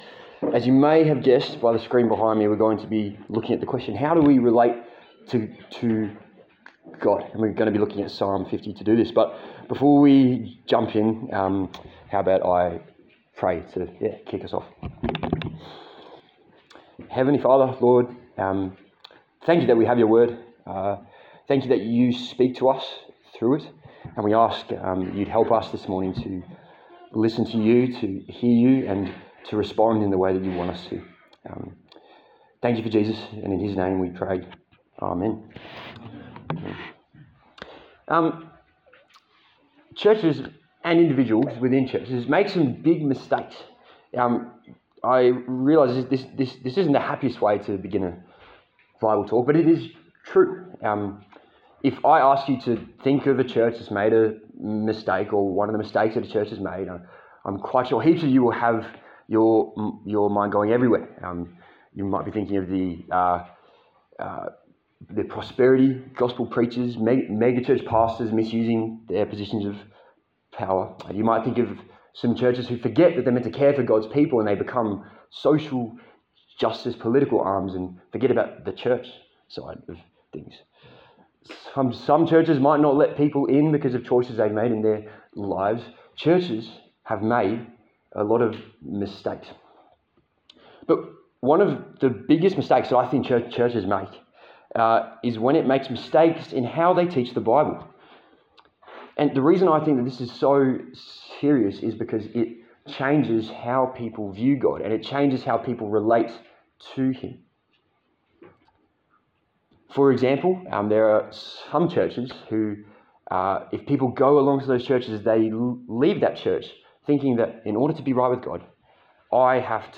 A sermon in the series on 'Songs of Summer' featuring the book of Psalms.